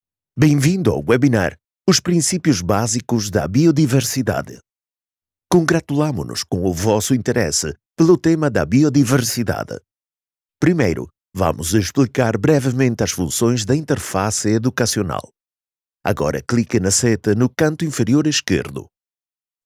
Voix
Commerciale, Distinctive, Accessible, Polyvalente, Fiable
E-learning